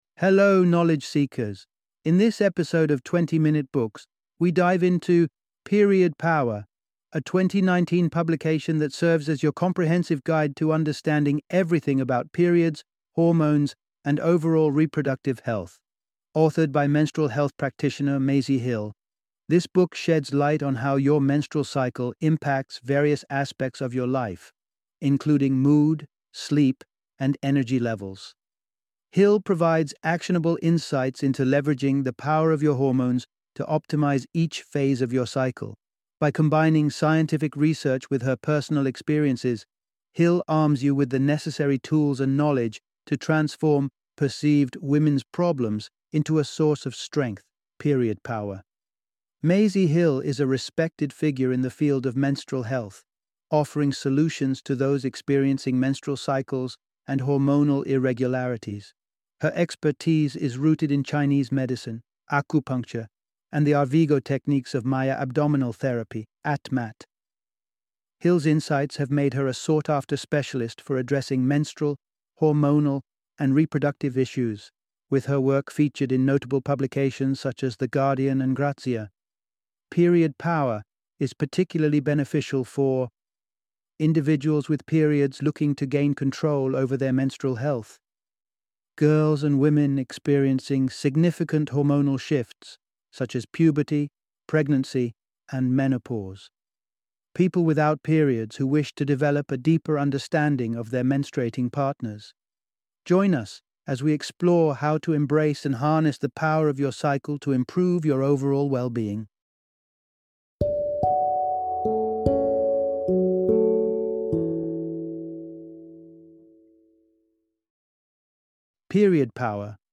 Period Power - Book Summary